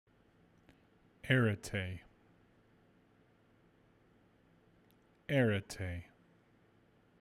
arete_pronunciation.m4a